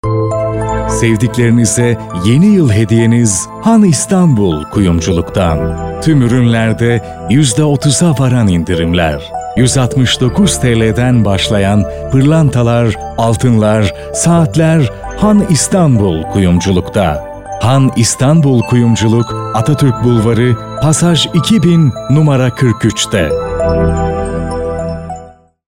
Masculino
Turkish Phone & IVR System
From medium friendly read to articulate and energetic hard-sell.